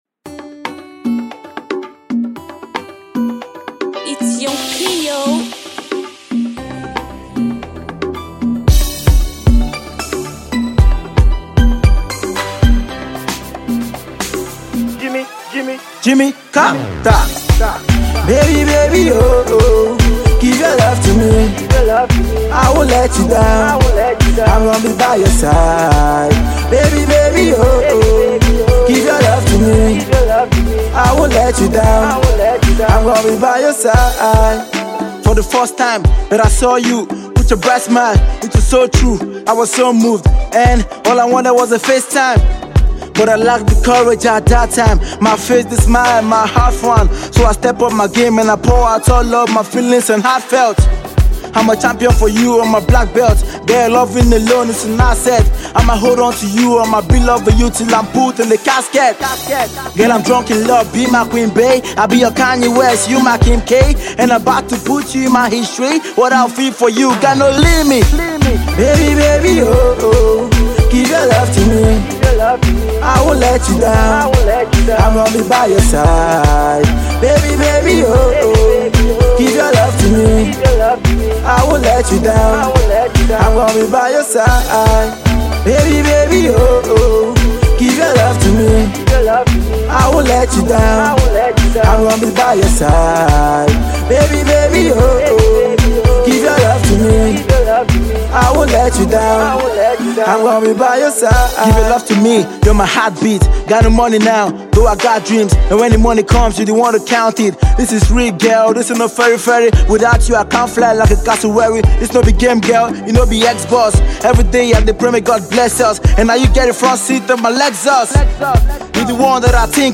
Alternative Rap
a love influenced wavy rap song